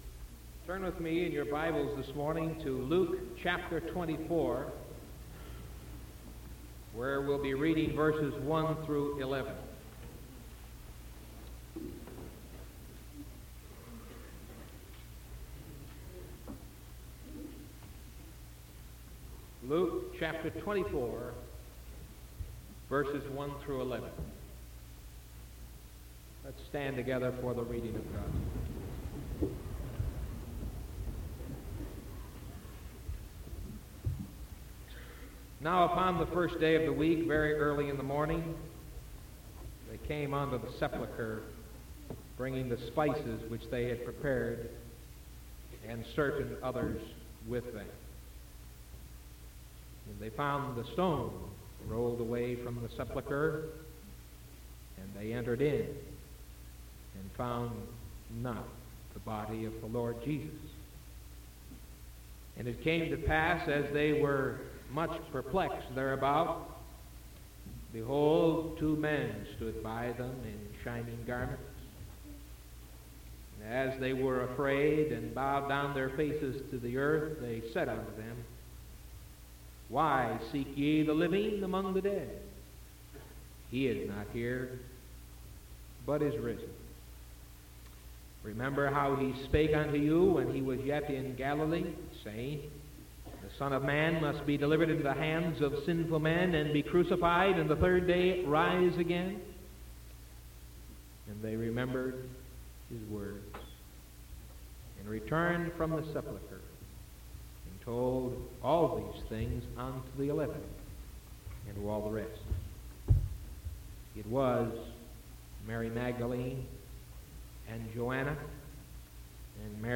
Sermon from March 24th 1974 AM